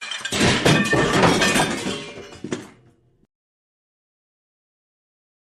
Scheppern Sound Effect Download: Instant Soundboard Button